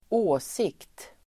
Uttal: [²'å:sik:t]